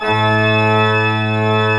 Index of /90_sSampleCDs/AKAI S6000 CD-ROM - Volume 1/VOCAL_ORGAN/CHURCH_ORGAN
ORG D1MF  -S.WAV